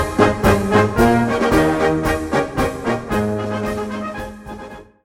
Traditions- und Regionalmärsche aus der Steiermark